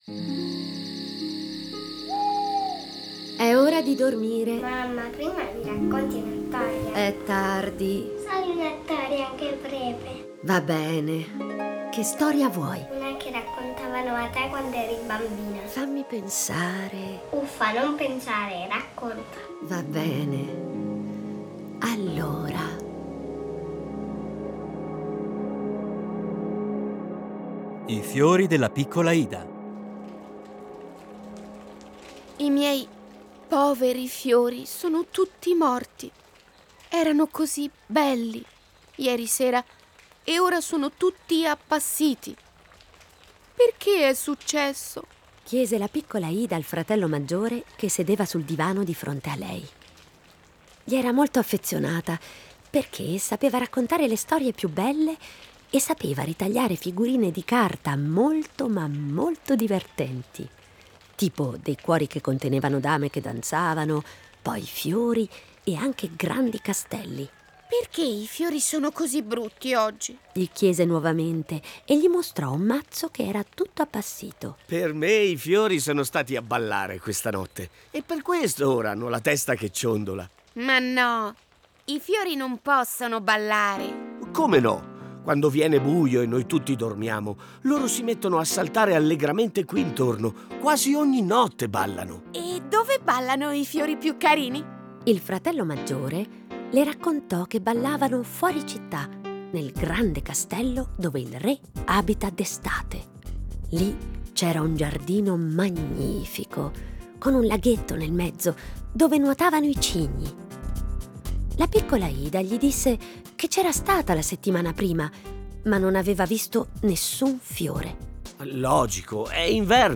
Fiabe
A partire dai testi originali un adattamento radiofonico per fare vivere i bambini storie conosciute, ma un po' dimenticate.